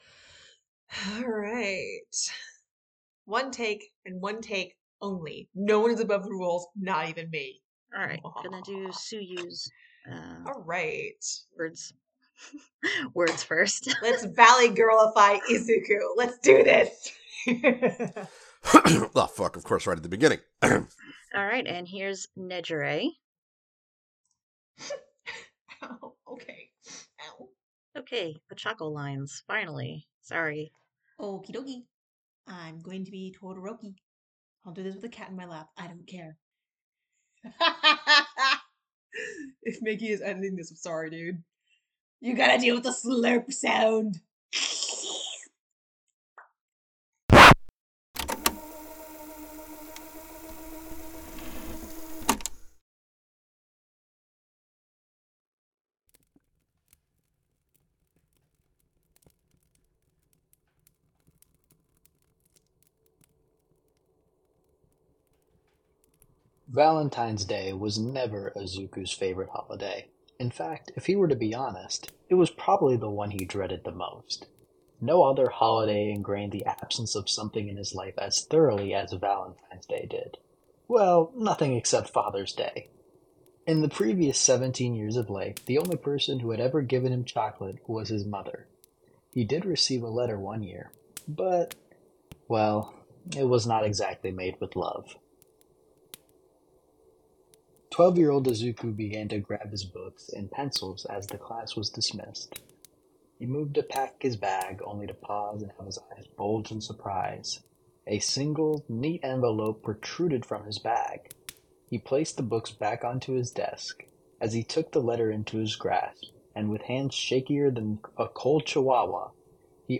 This is a crack podfic, meaning it's a joke parody of an otherwise not crack fic.